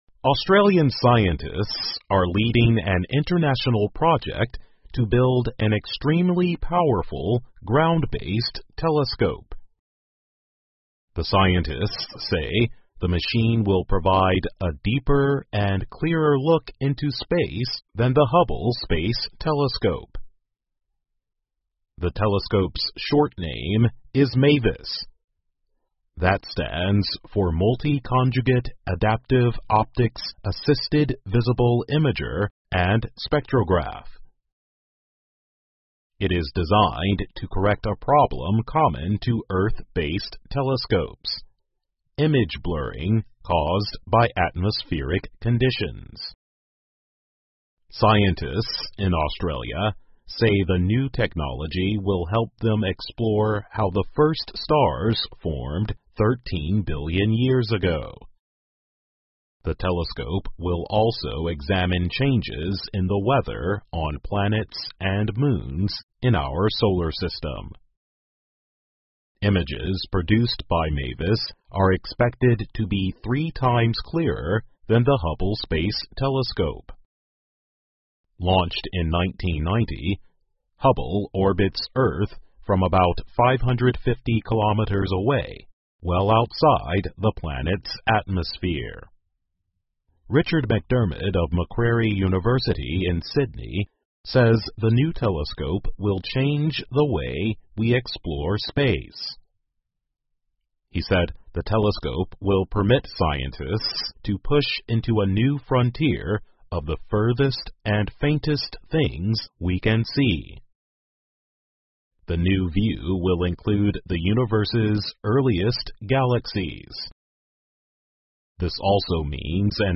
VOA慢速英语2021 澳大利亚打造超级望远镜 听力文件下载—在线英语听力室